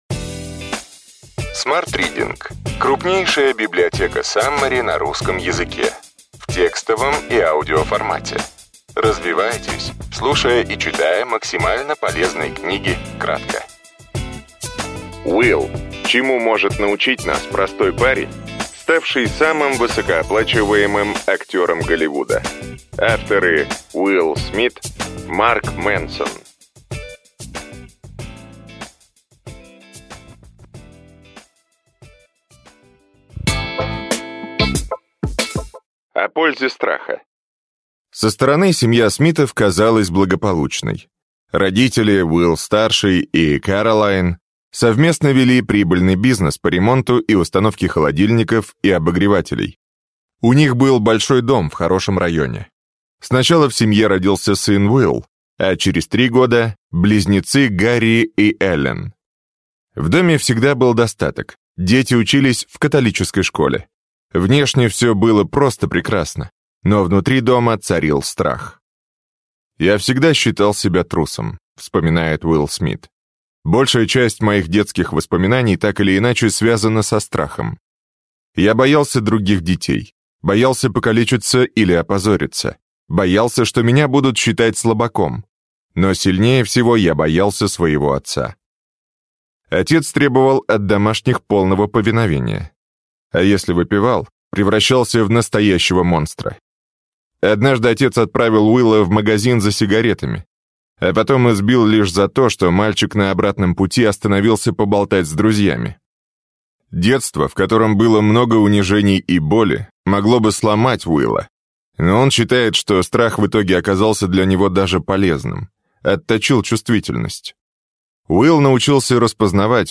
Аудиокнига Will. Чему может научить нас простой парень, ставший самым высокооплачиваемым актером Голливуда.